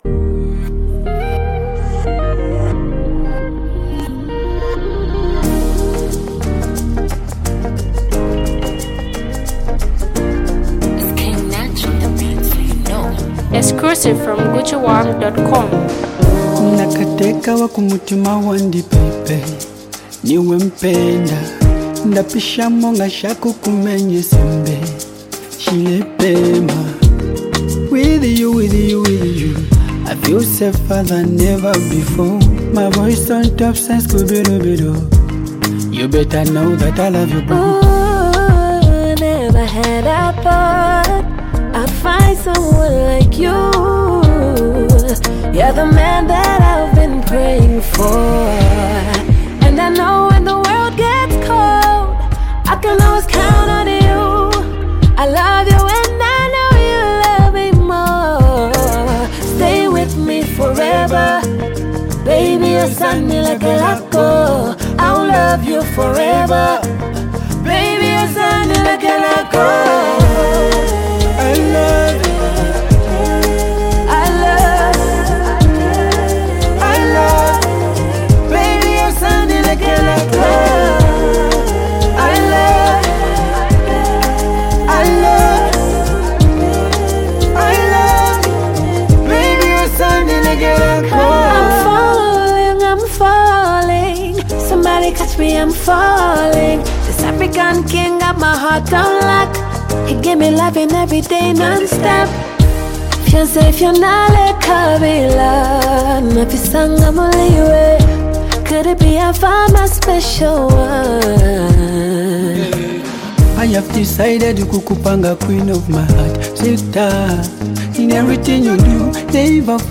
Qualified melodic rhyme